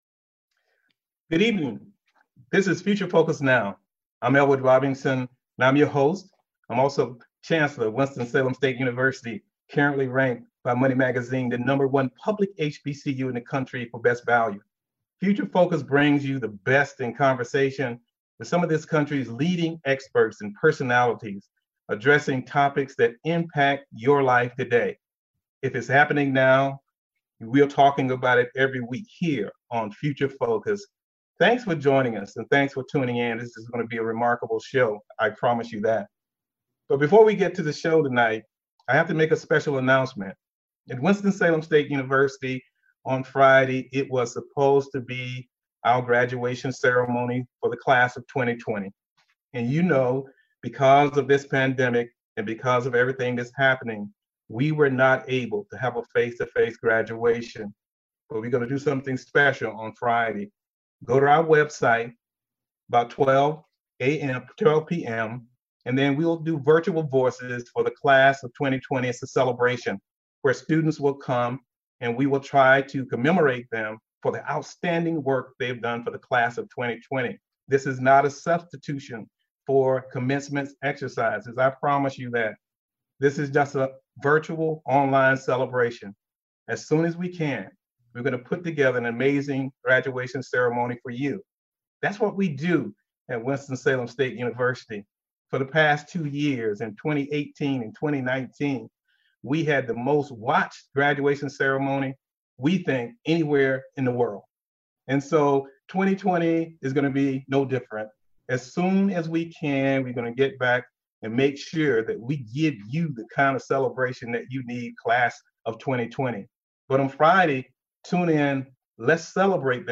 Future Focus is a one-hour public affairs talk show hosted by Winston-Salem State University's Chancellor Elwood Robinson. Everything WSSU! Community impact, latest campus news, campus life, student success stories, athletics, fundraising, legislative news, alumni and more.